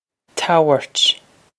Tow-ert
This is an approximate phonetic pronunciation of the phrase.